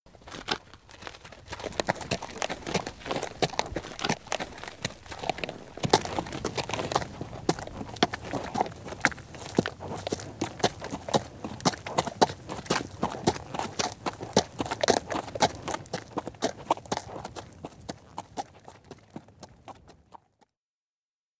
sound-of-walking-horses